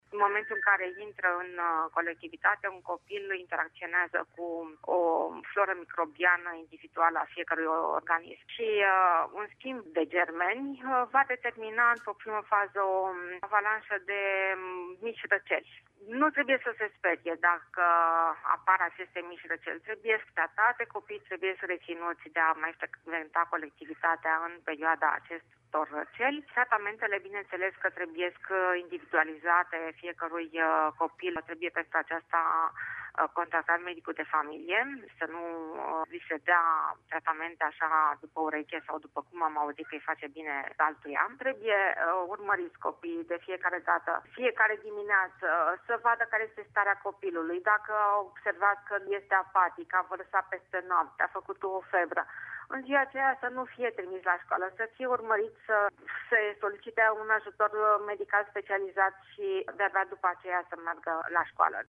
Medic